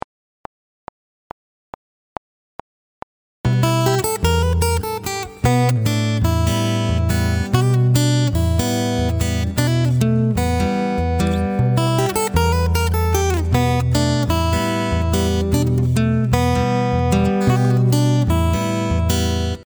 Chitarra Fingerstyle